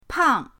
pang4.mp3